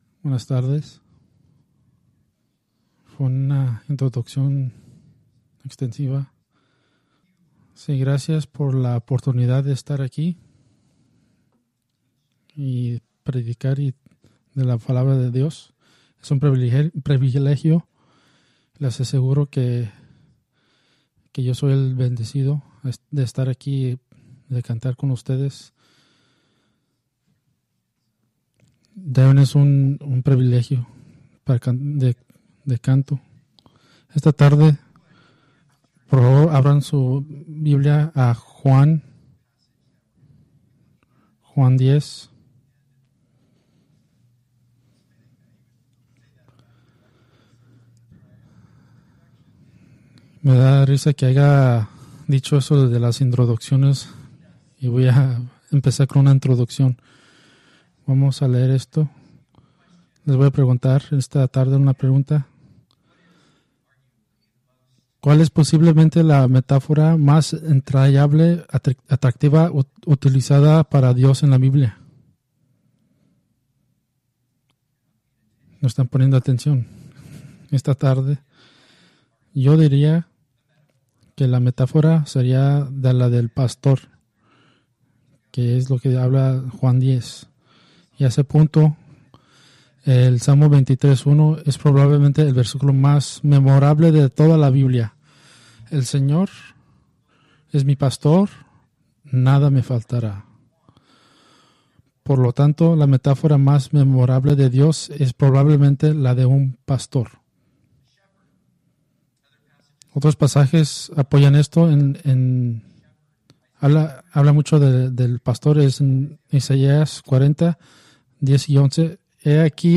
Preached July 28, 2024 from John 10:1-10